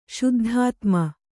♪ śuddhātma